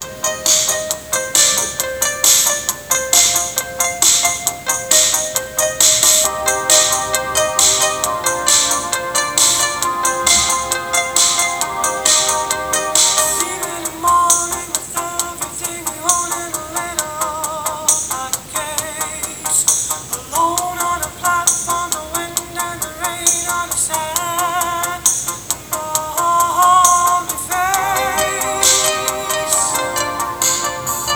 I just recorded music playing from my laptop, so yeah, the audio source isn't that great either, but you can hear the artifacts there.
Both were recorded lossless.
Unprocessed
Basically, it's just missing some noise-cancelling, but other than that, it's far better.